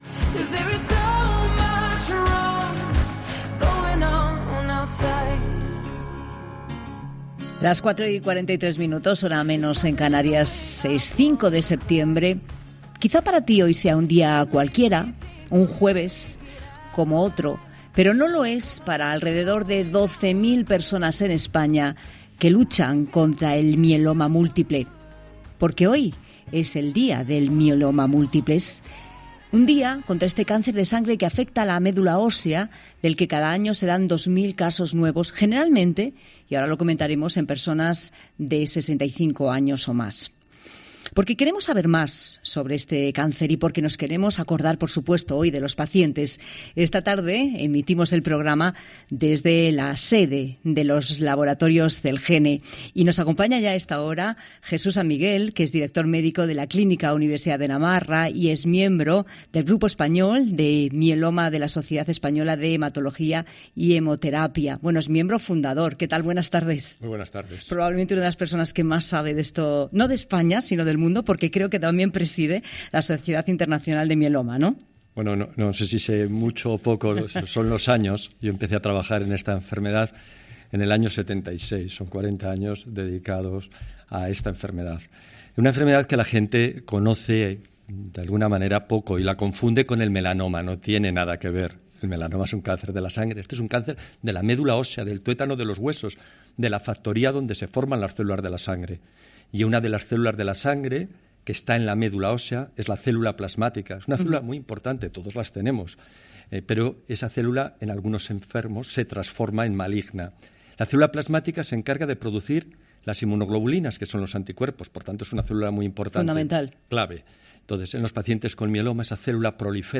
Y, por ello, desde 'La Tarde de COPE' hemos querido trasladarnos hasta la clínica Celgene en Madrid, desde donde hemos guardado un hueco especial para hablar del papel de España en la lucha contra esta enfermedad.